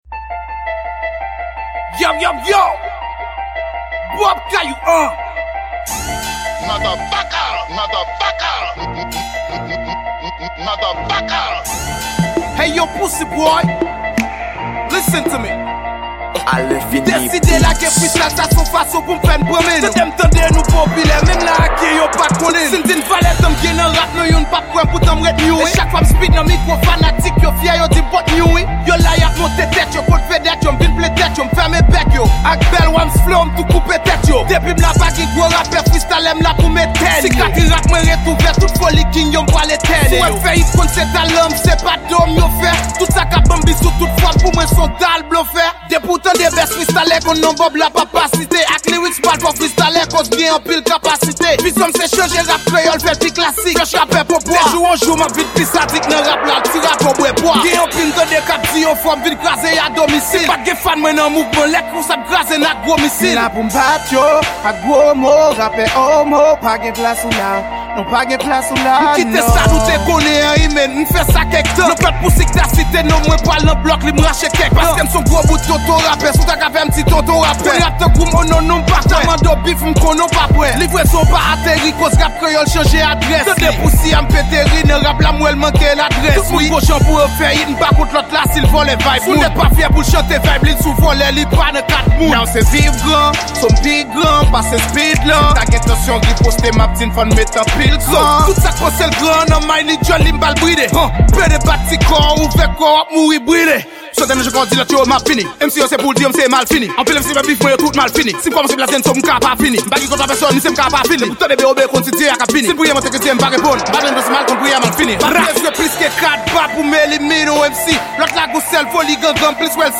Genre: Freestyle.